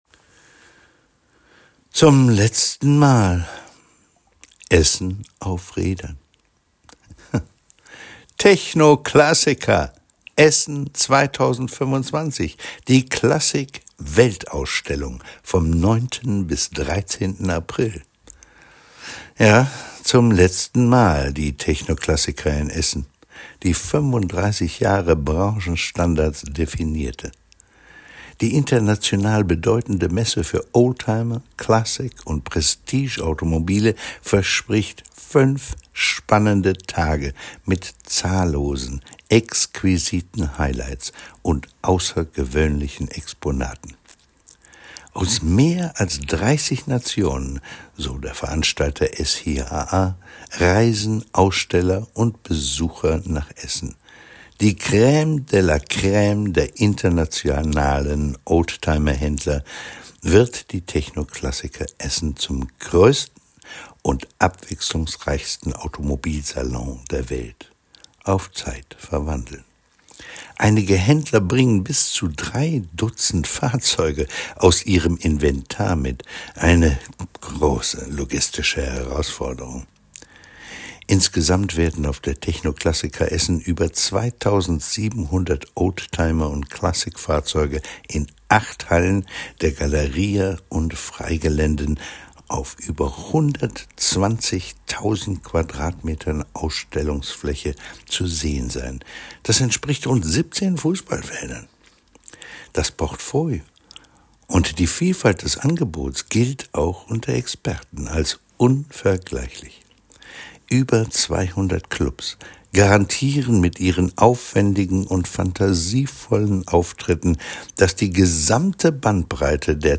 Im Interview könnt ihr auch checken, woher euch die Stimme noch bekannt vorkommt, seid gespannt!